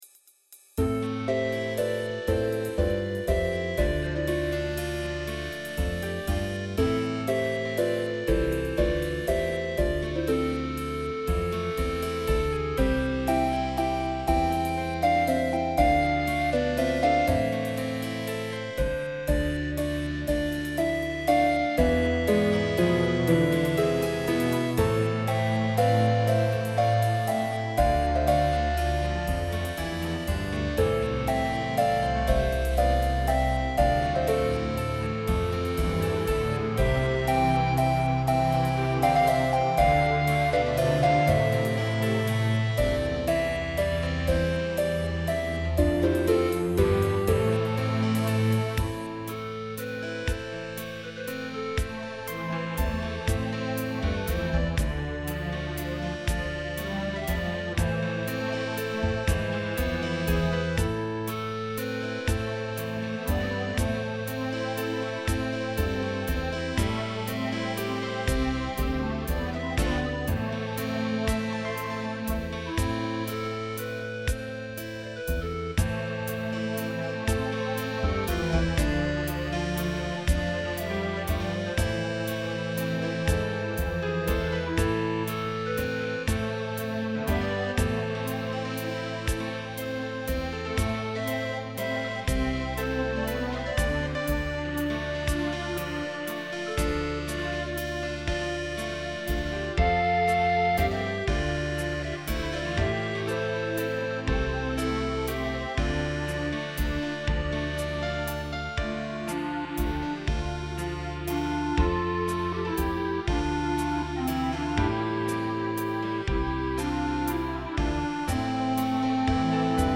לחן שלי, אינסטרומנטלי, לחוות דעתכם המלומדת
ניגנתי אותו בערוצים נפרדים באורגן VA-7 של ROLAND כלומר, בס בנפרד, כלי הקשה בנפרד, כלי נשיפה, סטרינגס, גיטרות וכו' הכל בנפרד.
ההקלטה בערוצים, באורגן עצמו, ולא בקיובייס, בו אין לי מושג. לא היה לי את כל הידע הנחוץ ליישום האפשרויות הקיימות באורגן ולכן, תוים "ברחו" או "התיישרו" לי אוטומטית , מה שמסביר את העיוותים הקלים פה ושם במשך היצירה. בסגירה, נשתנו לי סוגי הכלים ללא שביקשתי. יצירה נטולת אקו ואפקטים, שוב, מחוסר ידע מקיף, ולמרות הכל יש פה יצירה שאפשר "להבין אותה".